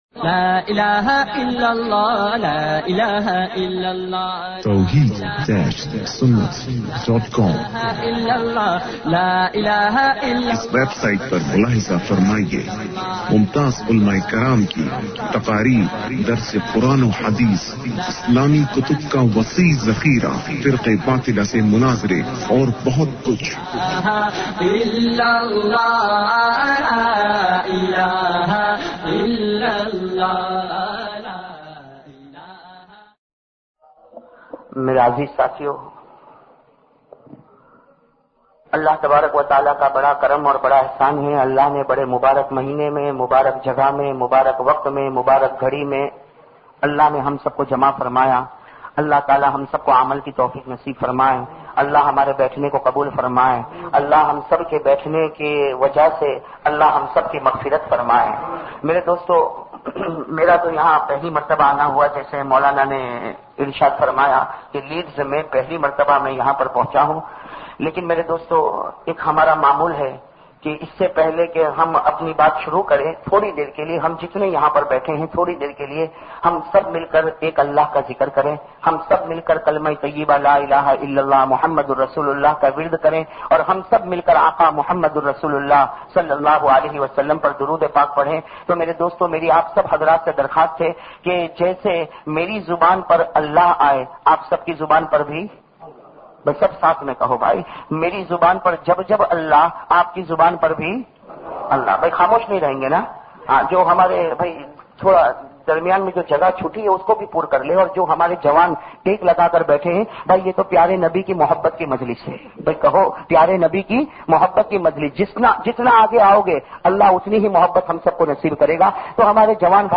Muhabat e Rasool bayan mp3